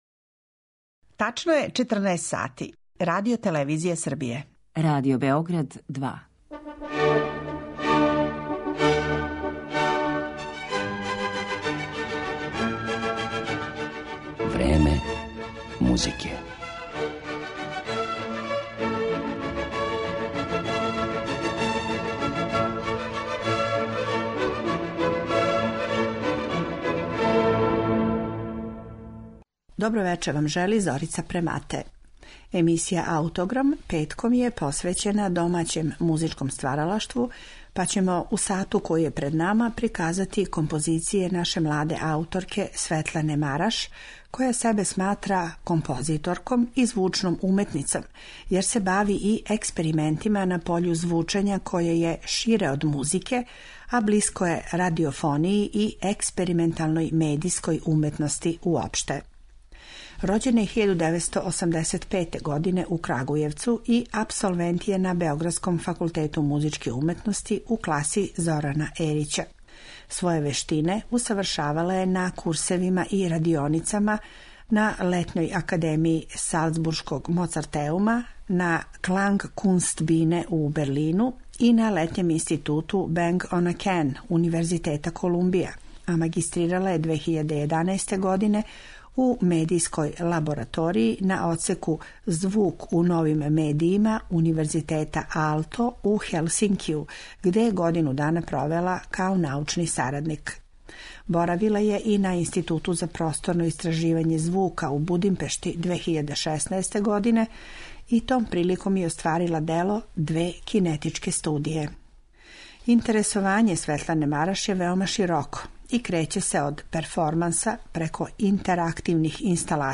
Снимак је са премијерног извођења, када су ово дело одсвирали чланови београдског ансамбла "Студио &" и базелске групе "Феникс".